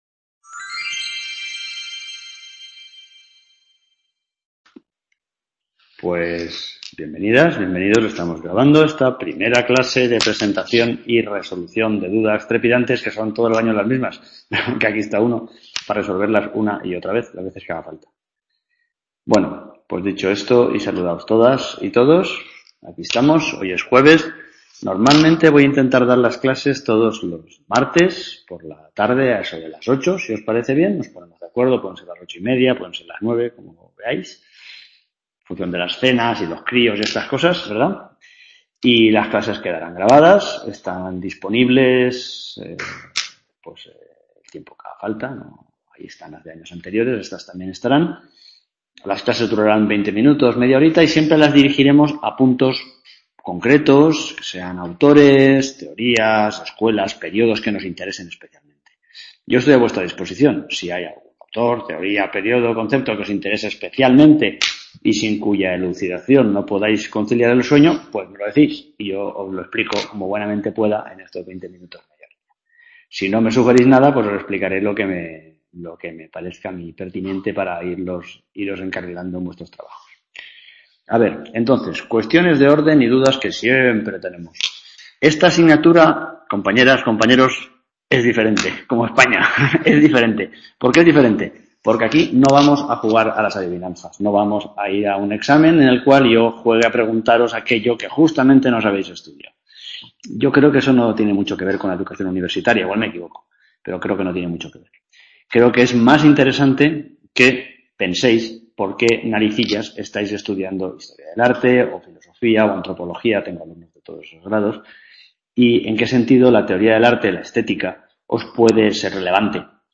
Clase de Presentacion o algo asi, Sept 2014 | Repositorio Digital